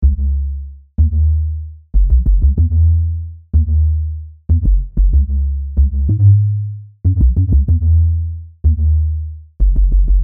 嘻哈 杜比斯特普 陷阱 竖琴
描述：嘻哈 杜比斯特普 陷阱 竖琴 非常顺利 寒冷 享受
Tag: 140 bpm Hip Hop Loops Harpsichord Loops 2.31 MB wav Key : Unknown